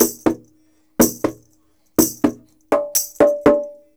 124-PERC6.wav